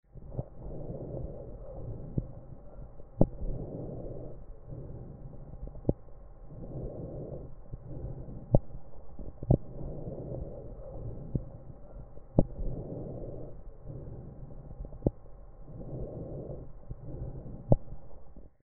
健常例 1